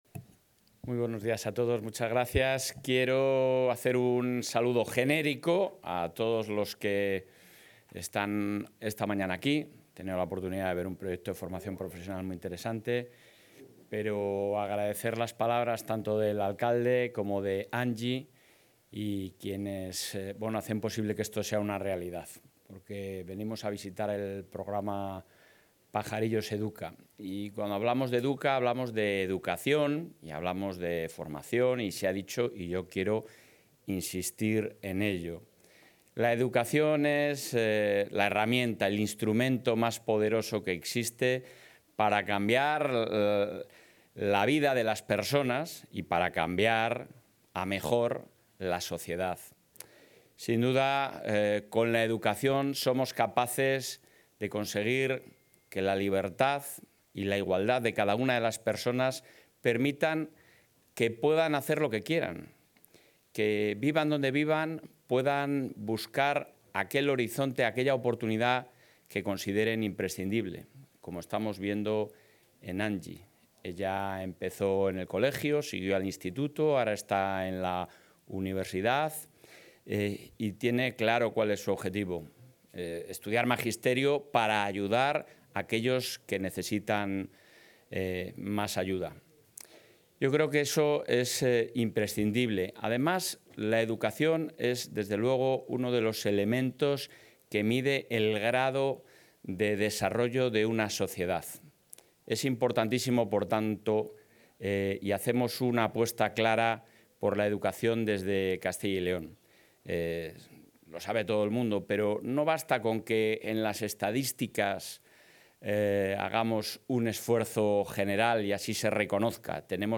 El presidente de la Junta de Castilla y León, Alfonso Fernández Mañueco, ha visitado hoy el barrio de Pajarillos, en Valladolid,...
Intervención del presidente de la Junta.